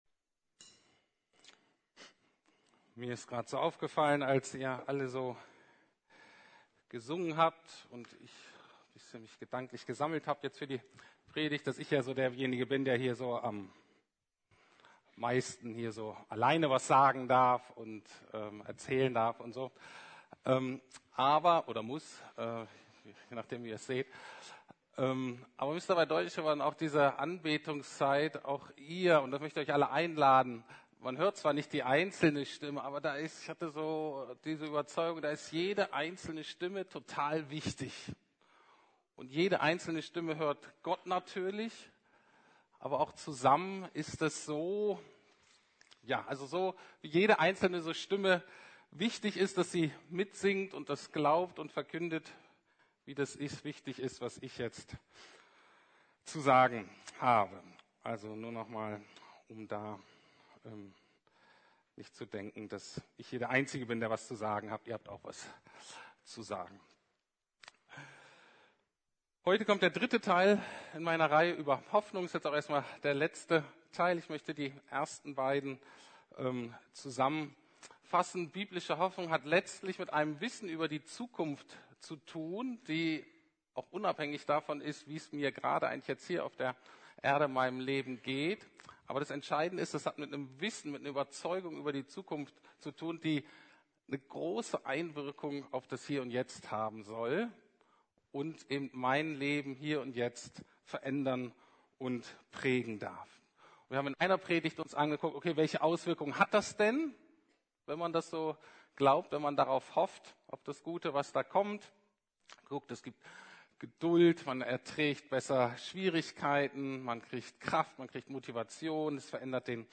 Der Gott der Hoffnung Teil 3 ~ Predigten der LUKAS GEMEINDE Podcast